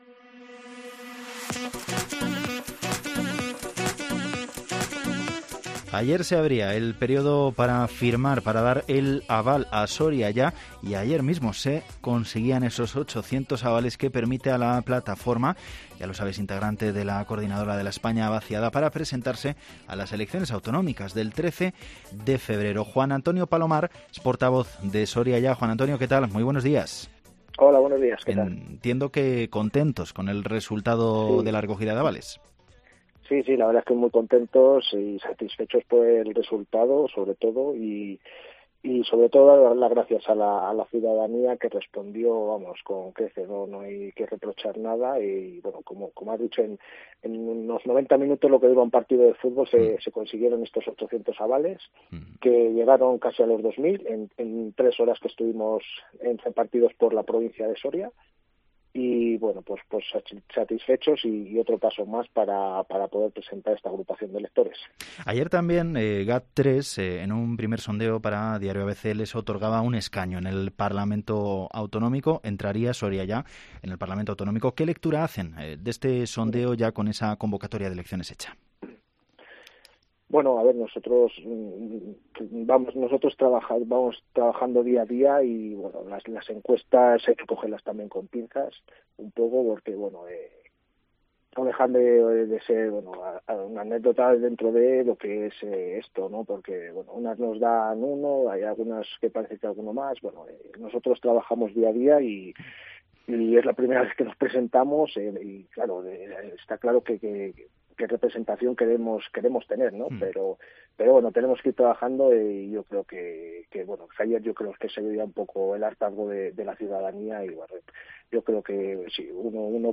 Valladolid - Tordesillas - Iscar